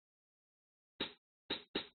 hi hat 11
描述：hi hat
Tag: 镲片 hi_hat Rides